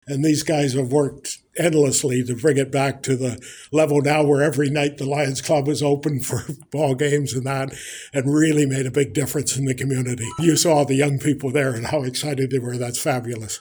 Former Dutton-Dunwich Mayor, Bob Purcell called the grant announcement “fabulous” and is a tribute to the outstanding efforts of so many community members.